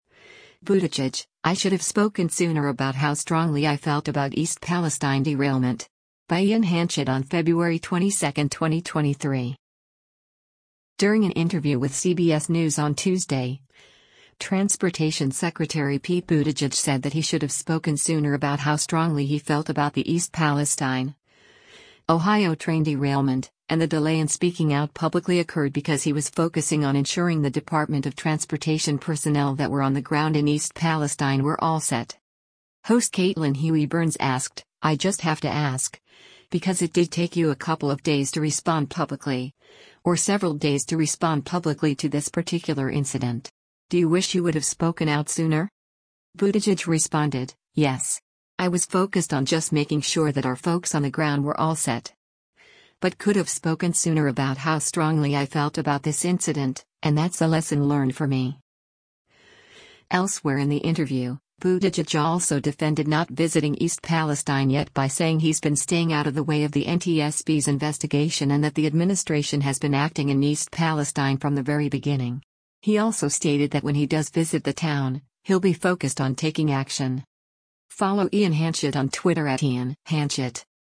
During an interview with CBS News on Tuesday, Transportation Secretary Pete Buttigieg said that he should have “spoken sooner about how strongly” he felt about the East Palestine, Ohio train derailment, and the delay in speaking out publicly occurred because he was focusing on ensuring the Department of Transportation personnel that were on the ground in East Palestine “were all set.”